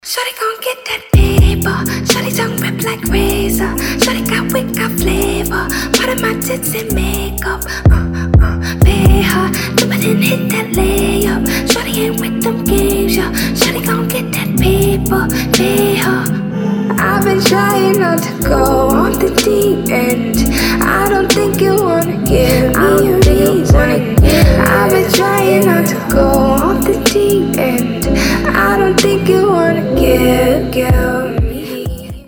Гитара , RnB
Атмосферные , Женский голос
Поп